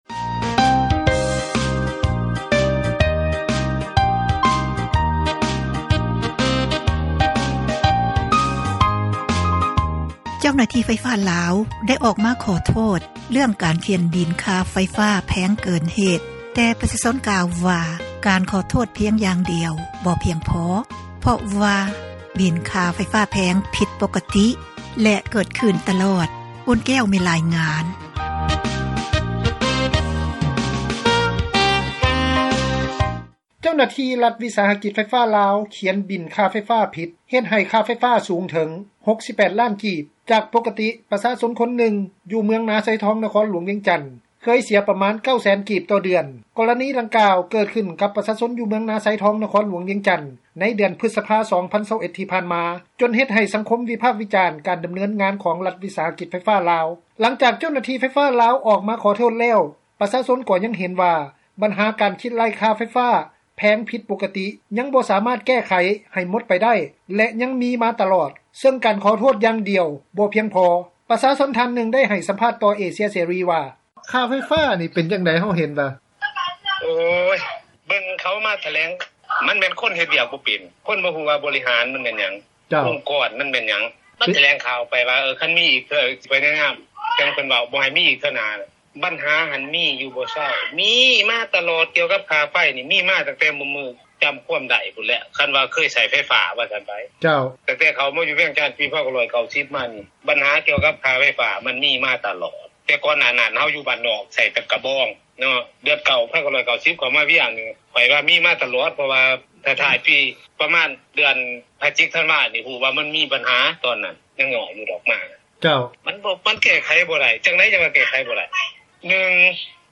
ນັກຂ່າວ ພົລເມືອງ
ປະຊາຊົນ ທ່ານນຶ່ງ ໄດ້ໃຫ້ສັມພາດ ຕໍ່ເອເຊັຽເສຣີ ວ່າ: